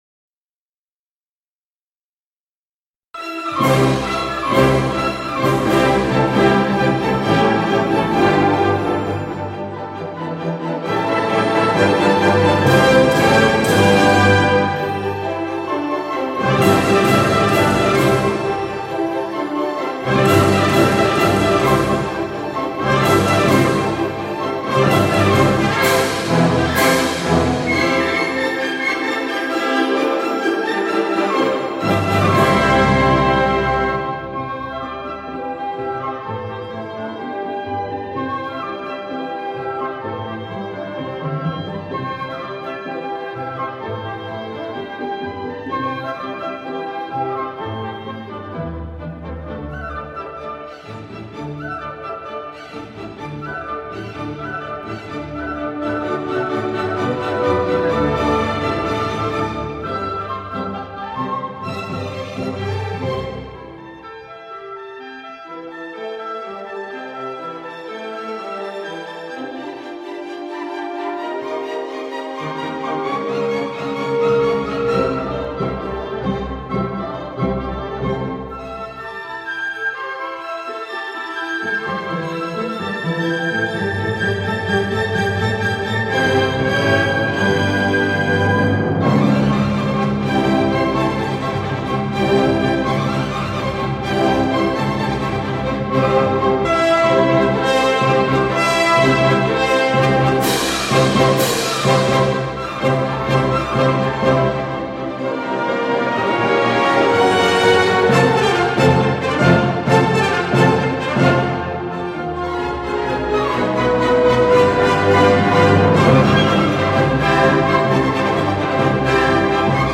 乐曲一陕北秧歌和民歌为素材，乐曲欢快热烈，情趣盎然，风格浓郁。